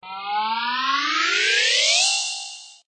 object_grow_1.ogg